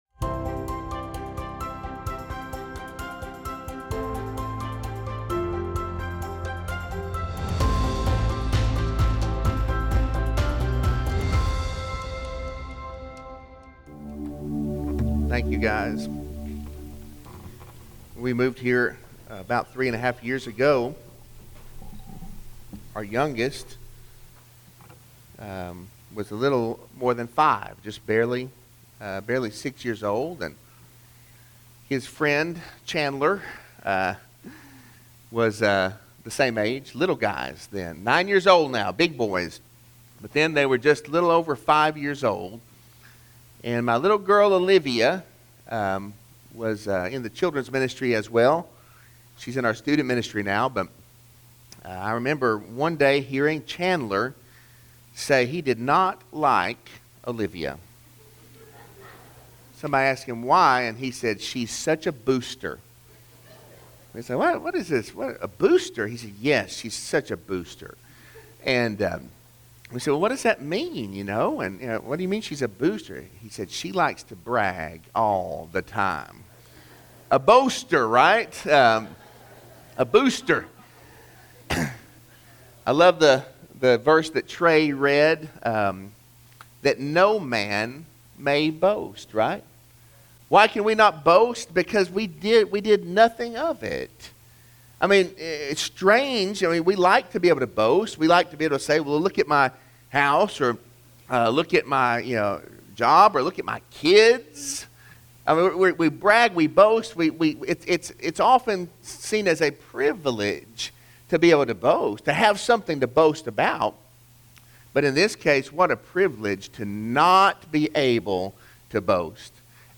Sermon-9-19-21-audio-from-video.mp3